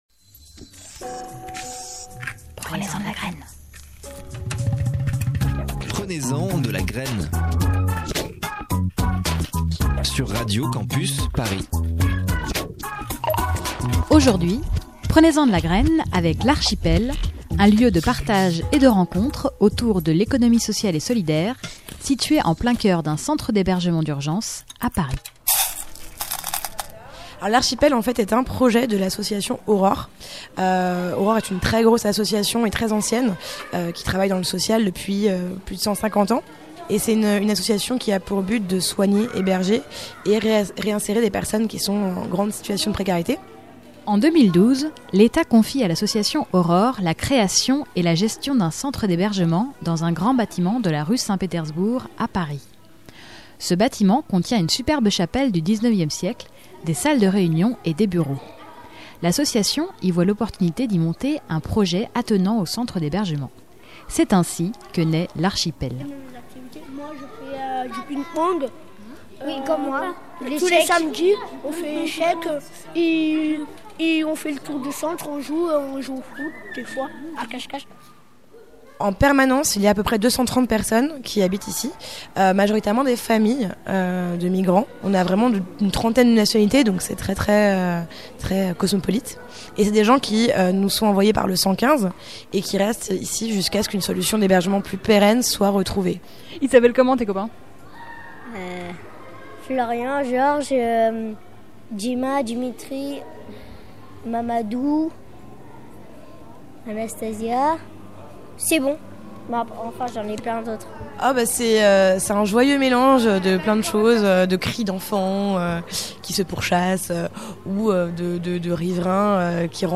quelques bambins qui ont fait de ce lieu leur principal terrain de jeu.
Interview et montage